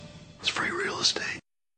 Whisper